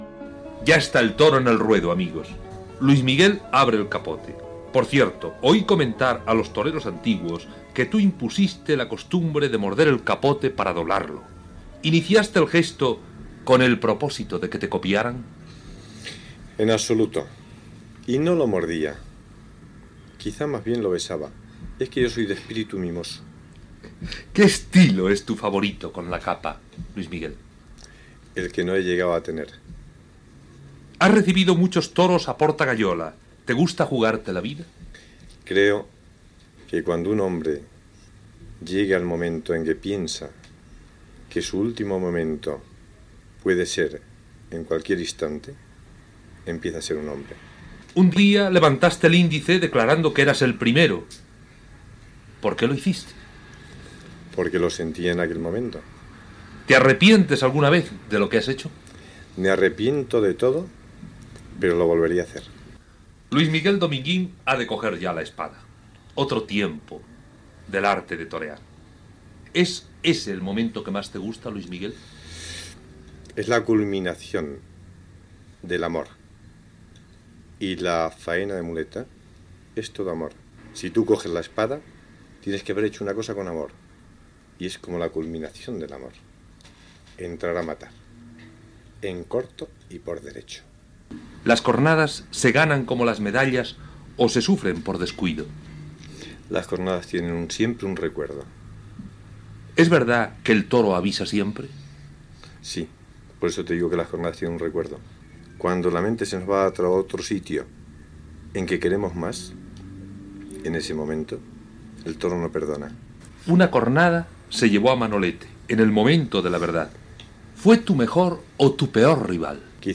Entrevista al torero Luis Miguel Dominguín
Entreteniment